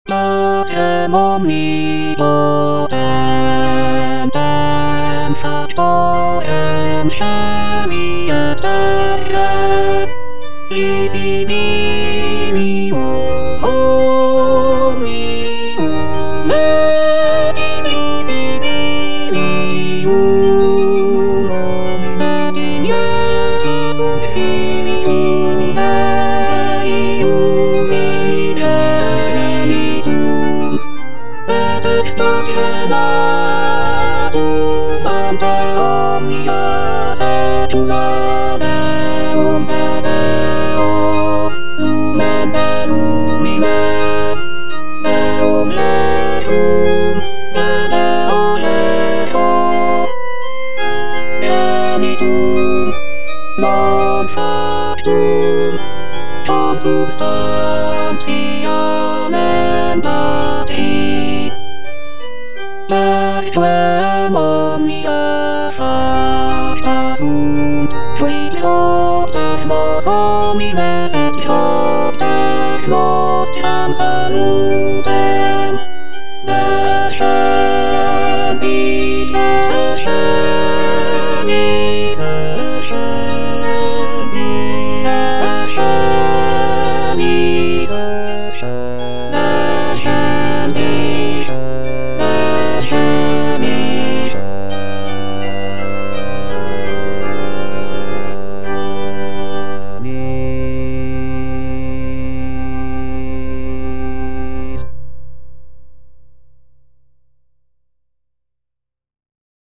J.G. RHEINBERGER - MASS IN C OP. 169 Credo - Patrem omnipotentem - Alto (Emphasised voice and other voices) Ads stop: auto-stop
This composition is known for its rich textures, expressive melodies, and intricate interplay between the vocal and instrumental sections, making it a notable contribution to the genre of sacred choral music.